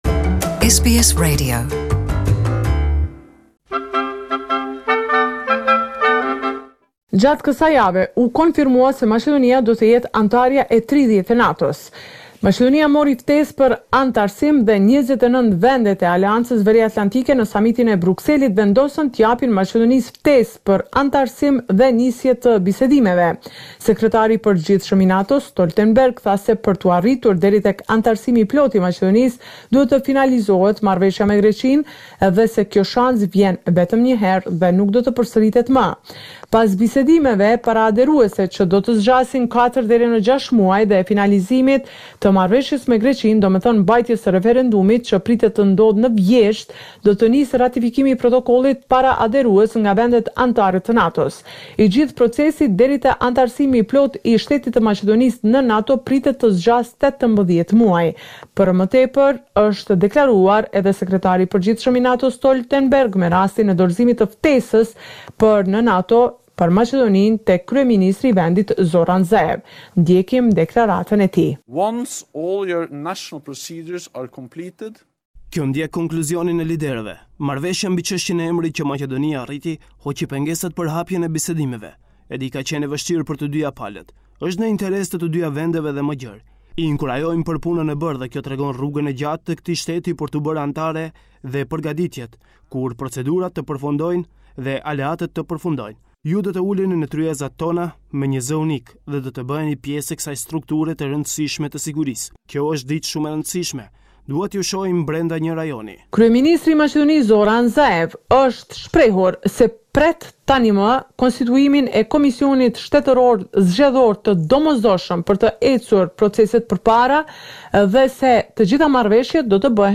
The weekly report with the latest developments in Macedonia.